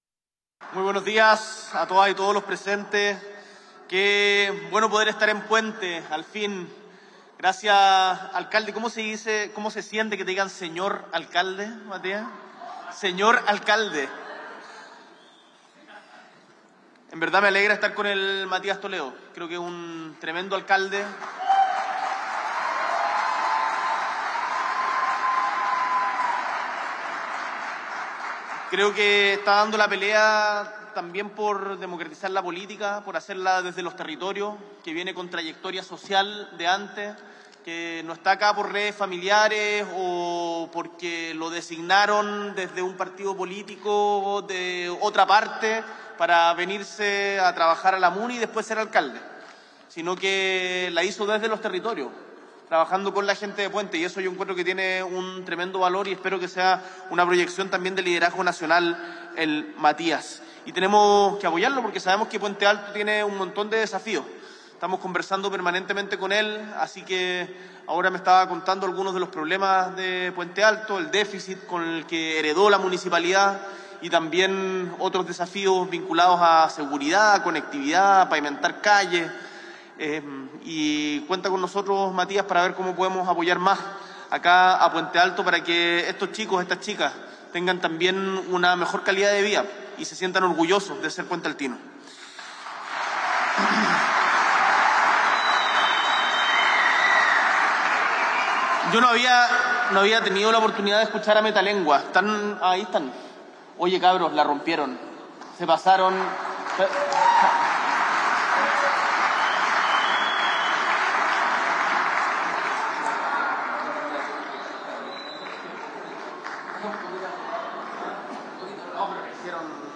S.E. el Presidente de la República, Gabriel Boric Font, participa de la feria "Activa tu Pase Cultural" en Puente Alto
Discurso